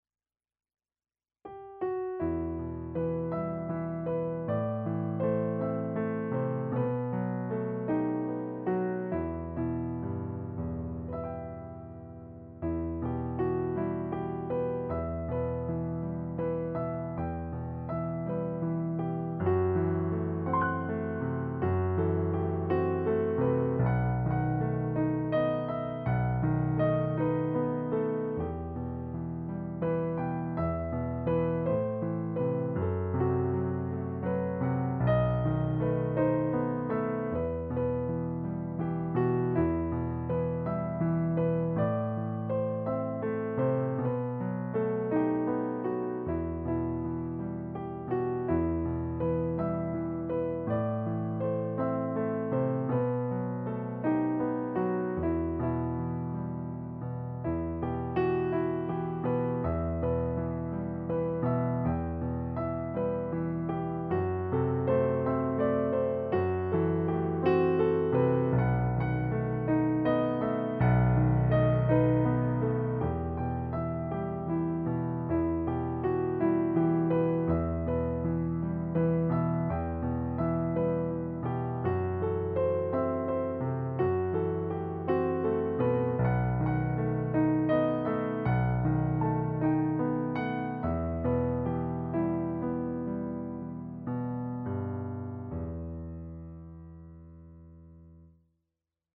Piano s/ Clique